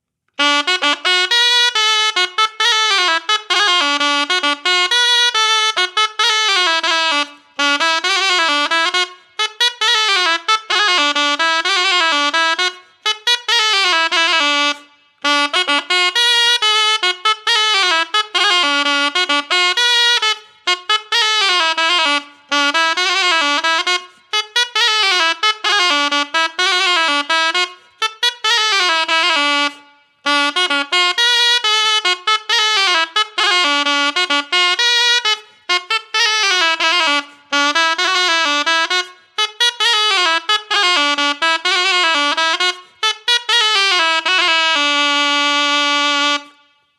Aire culturelle : Couserans
Lieu : Lamothe-Cassel
Genre : morceau instrumental
Instrument de musique : aboès
Danse : castanha